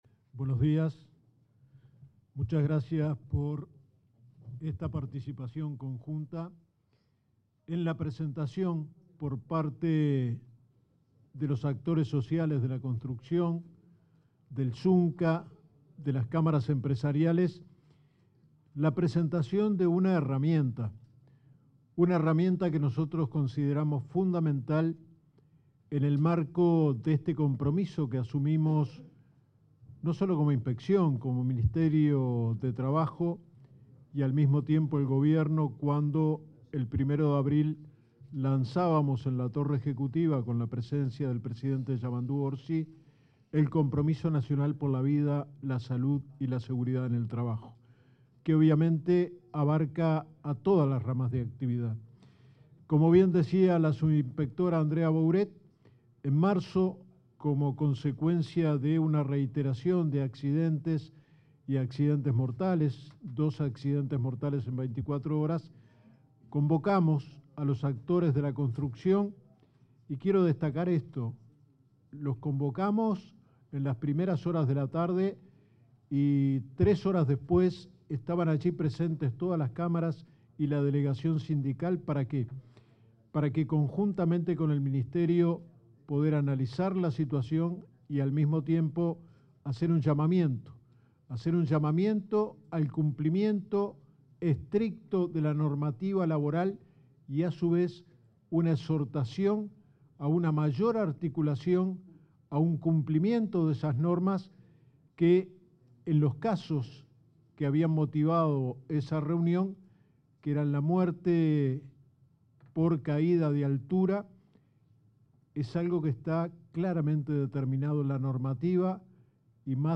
Palabras de autoridades del Ministerio de Trabajo sobre seguridad laboral
Palabras de autoridades del Ministerio de Trabajo sobre seguridad laboral 14/07/2025 Compartir Facebook X Copiar enlace WhatsApp LinkedIn El inspector general de Trabajo, Luis Puig, y el ministro de Trabajo y Seguridad Social, Juan Castillo, expusieron acerca de la nueva campaña de sensibilización sobre seguridad laboral.